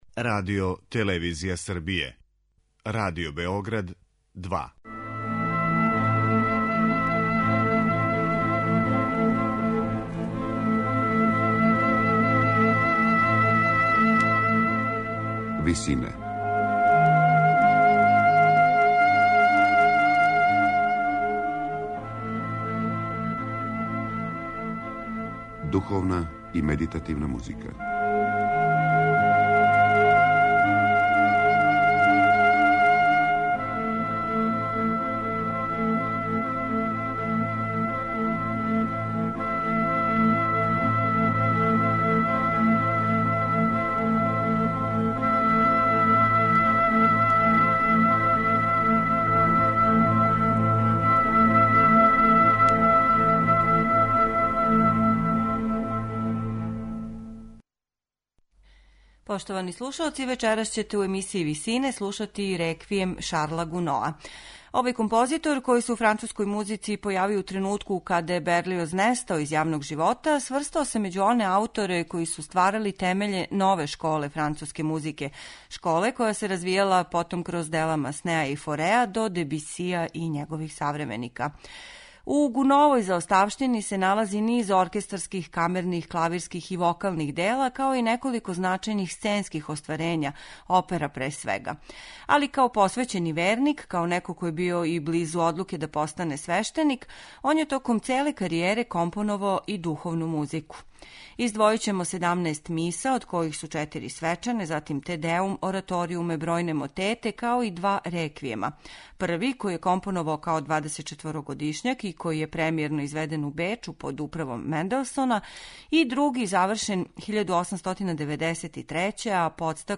Емитоваћемо снимак који су остварили вокални и инструментални ансамбл из Лозане. Дириговао је Мишел Корбос.